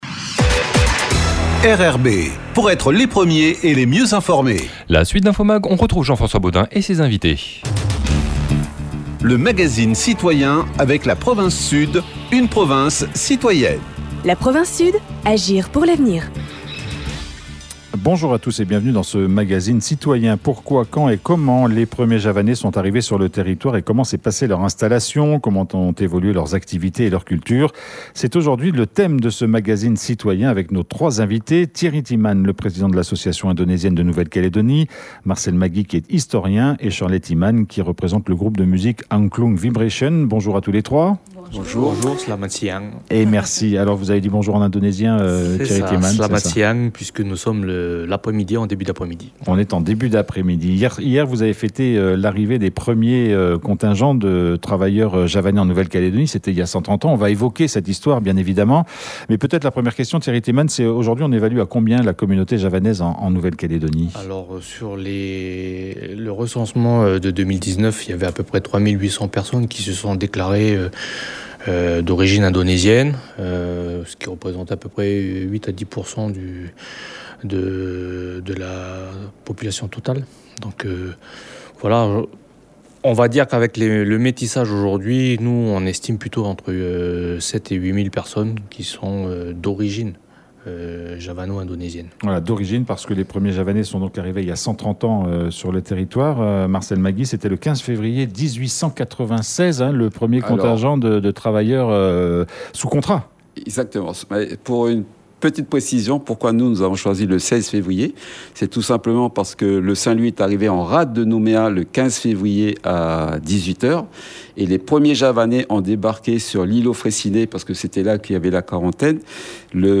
Pourquoi, où, quand, et comment les premiers javanais sont arrivés sur le territoire et comment s’est passé leur installation ? Comment ont évolué leurs activités et leur culture ? C'était ce midi le thème du magazine Citoyen.